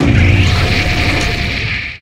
Cri de Rampe-Ailes dans Pokémon HOME.